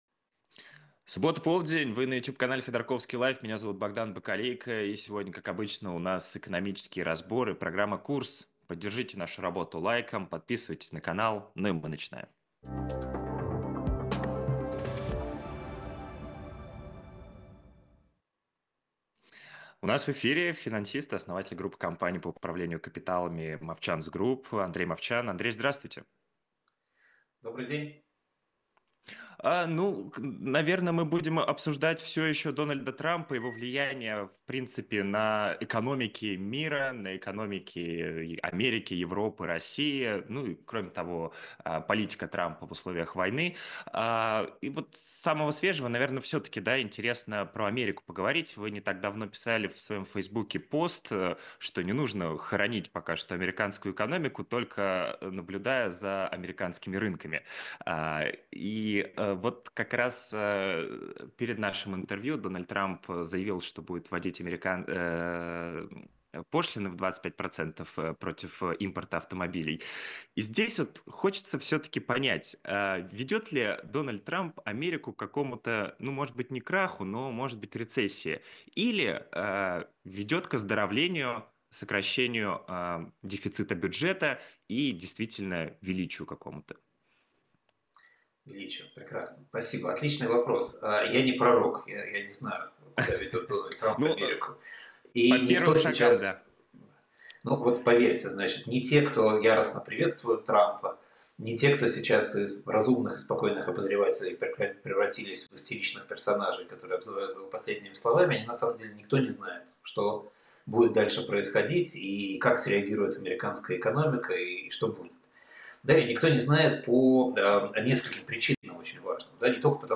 Андрей Мовчан экономист